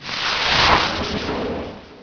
rocket.wav